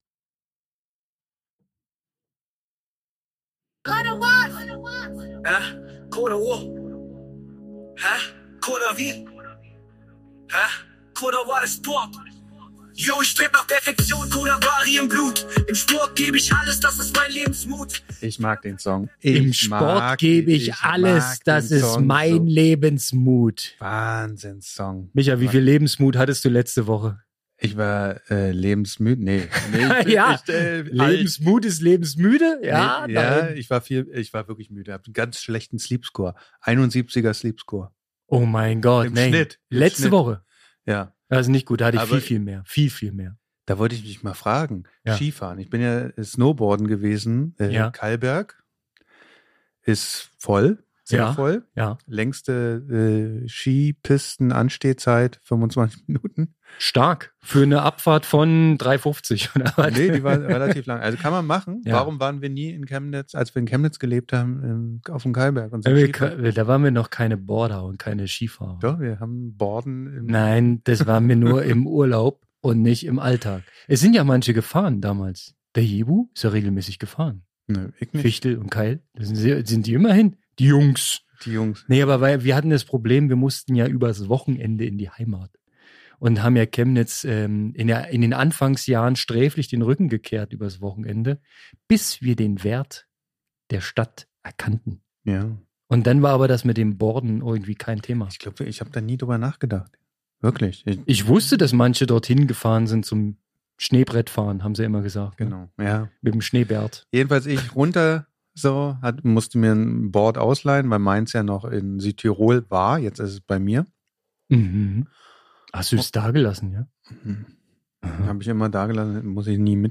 Zurück aus dem Urlaub und sofort ins Podcaststudio der Sportmacher.